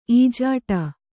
♪ ījāṭa